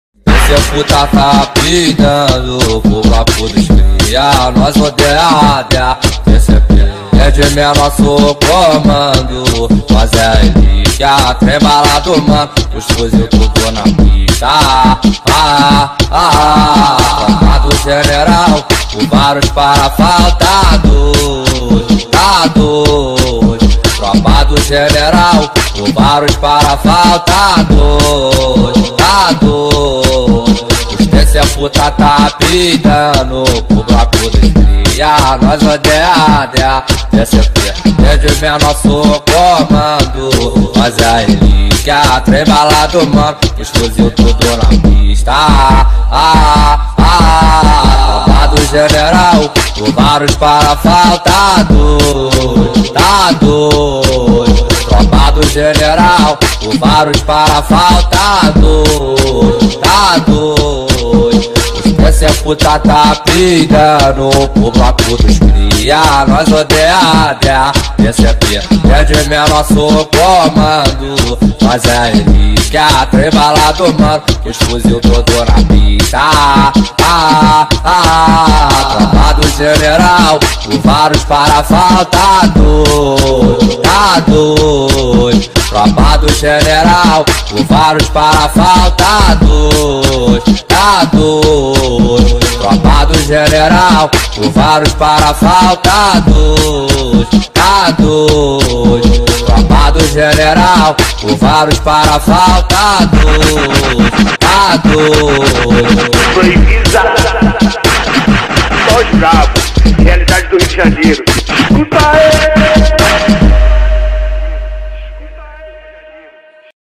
2024-09-17 08:16:45 Gênero: Funk Views